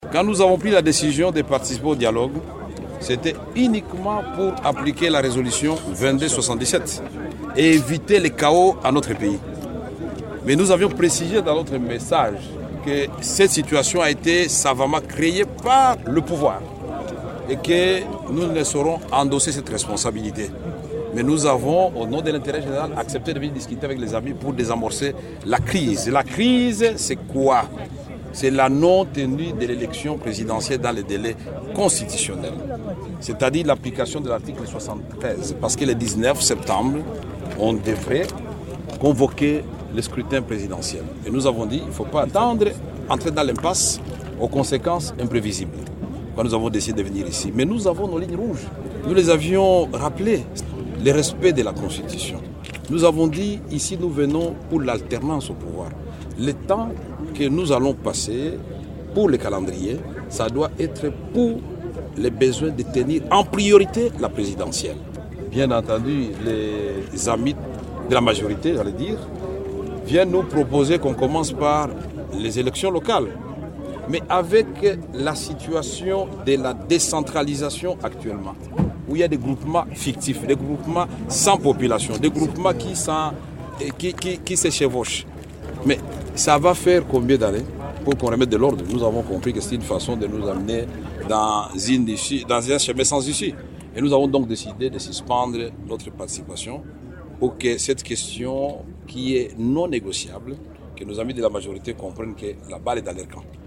Vital Kamerhe est au micro de TOPCONGO FM, notre station partenaire à Kinshasa